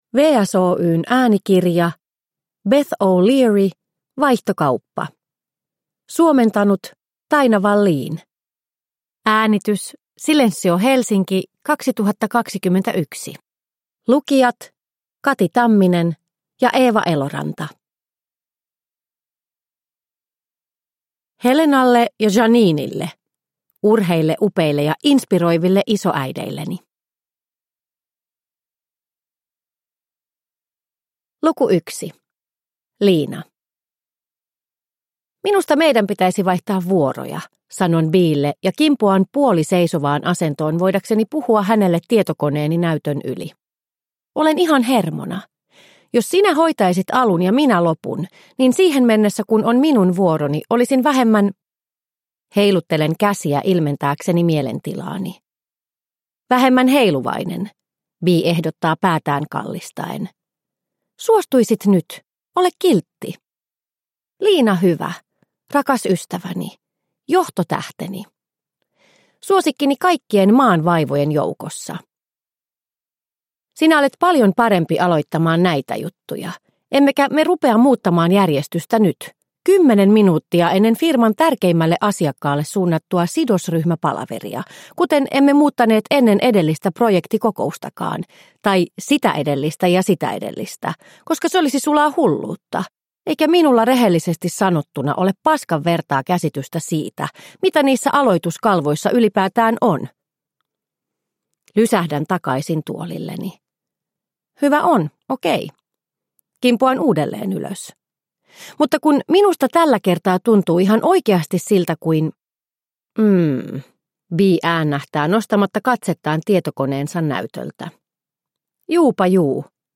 Vaihtokauppa – Ljudbok – Laddas ner